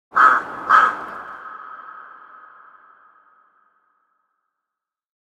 Spooky Crow Caw Caw With Echo Sound Effect
Description: Spooky crow caw caw with echo sound effect. Create a spooky, atmospheric effect with this eerie bird call featuring echo.
Genres: Sound Effects
Spooky-crow-caw-caw-with-echo-sound-effect.mp3